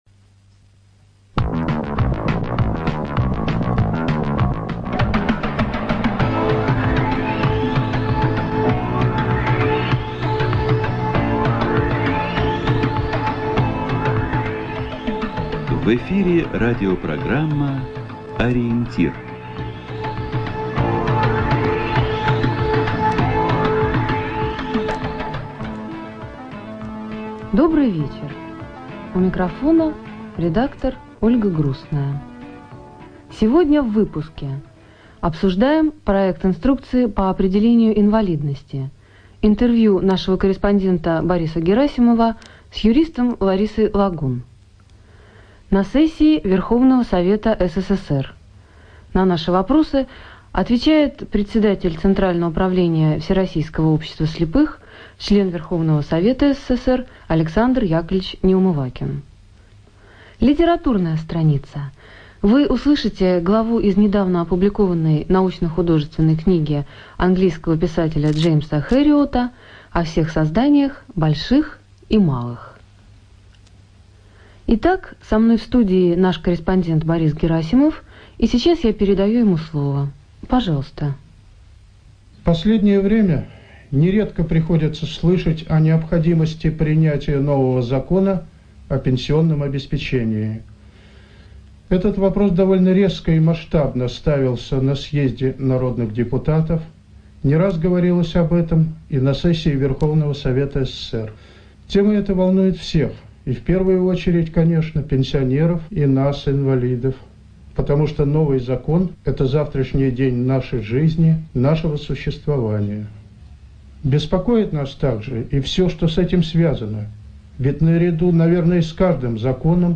ЖанрРеабилитация, Радиопрограммы